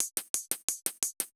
Index of /musicradar/ultimate-hihat-samples/175bpm
UHH_ElectroHatA_175-01.wav